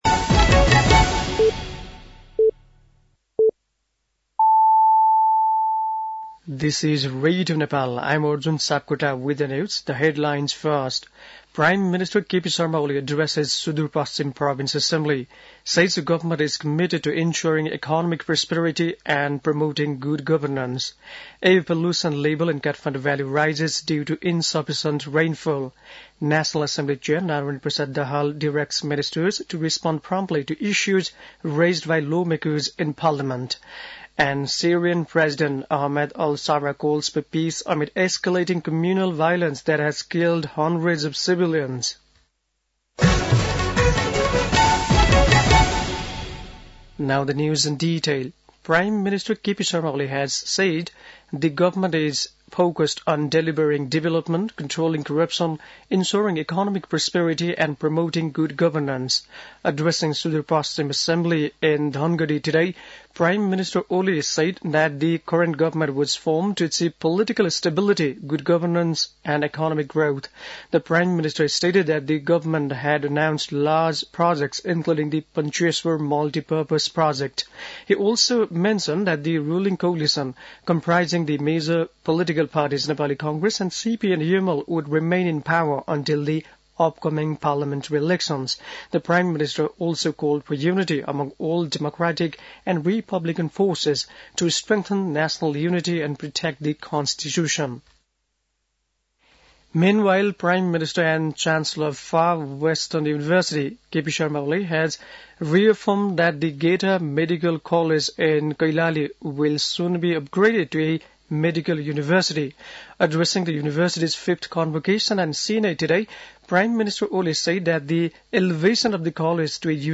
बेलुकी ८ बजेको अङ्ग्रेजी समाचार : २६ फागुन , २०८१
8-pm-news-1.mp3